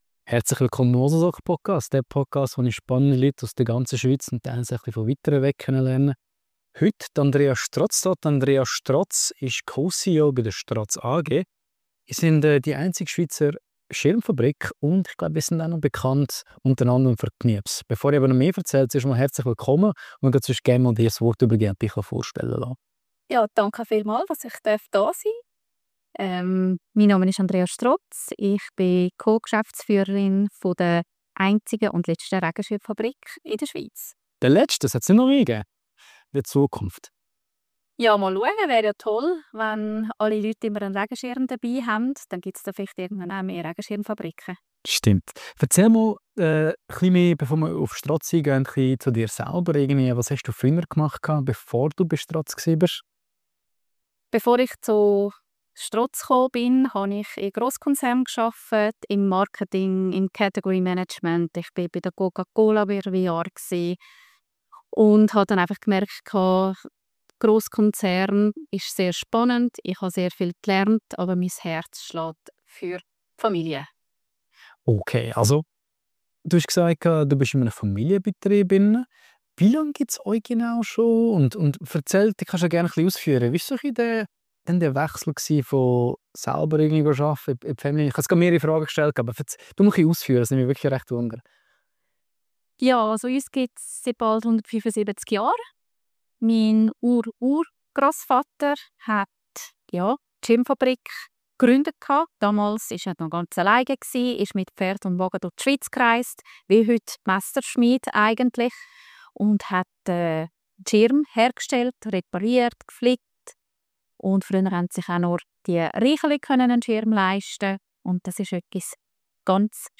Wir sprechen über Knirps als Kultmarke, über den Traum, die Produktion wieder nach Europa zu holen, und darüber, was Druck in der fünften Generation wirklich heisst. Ein Gespräch über Unternehmertum, Familientradition und den Mut, Altes neu zu denken.